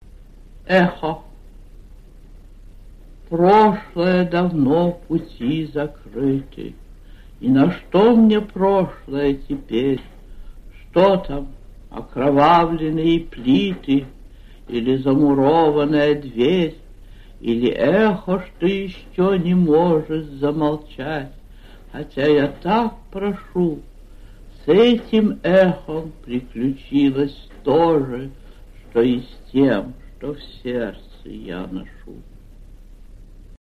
6. «Читает (Горенко) Ахматова Анна Андреевна – 31. Эхо» /